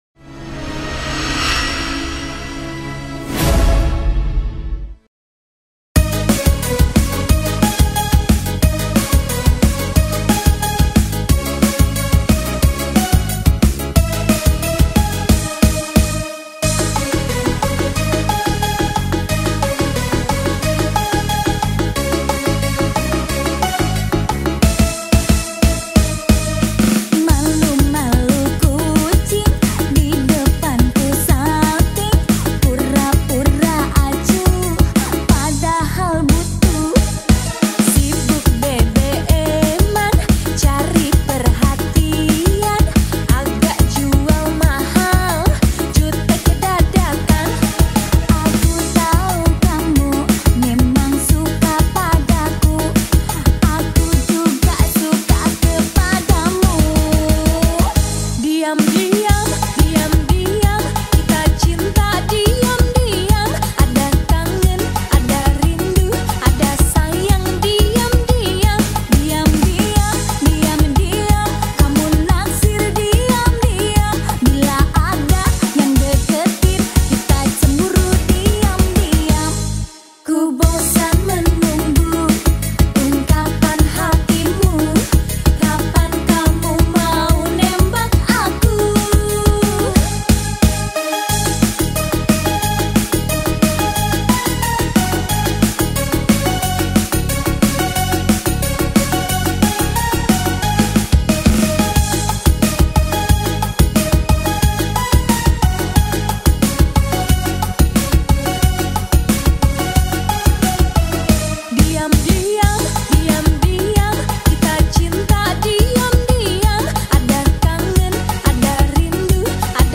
Dangdut